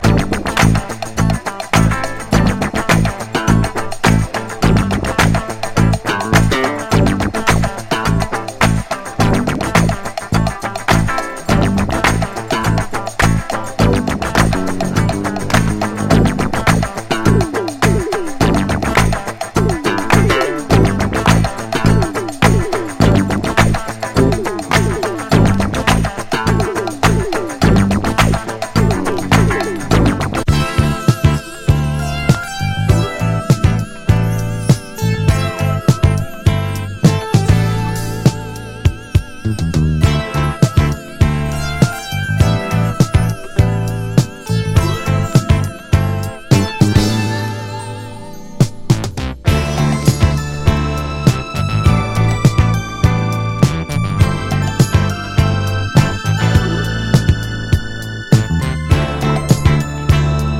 南部ファンクバンド ’78年作。クラッピン・シンセファンク
メロウグルーヴ